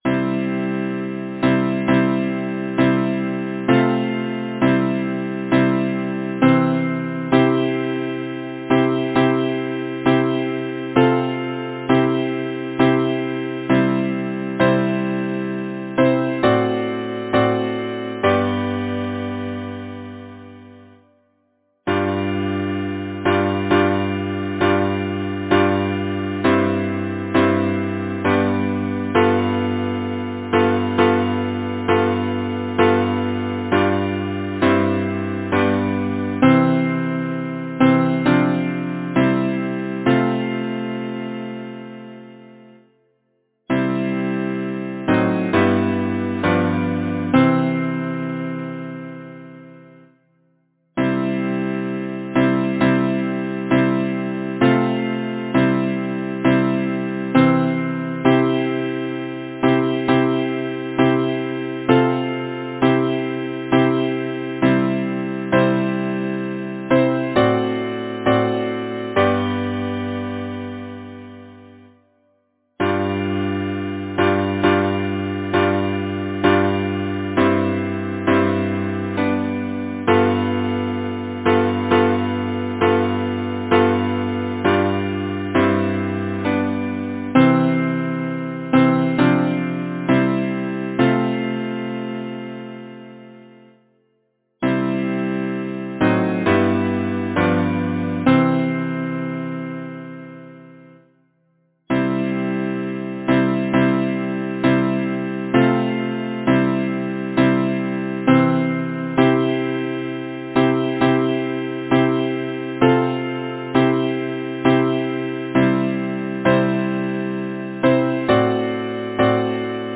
Title: Through the clouds of sorrow beaming Composer: Oliver Day Adams Lyricist: Number of voices: 4vv Voicing: SATB Genre: Secular, Partsong
Language: English Instruments: A cappella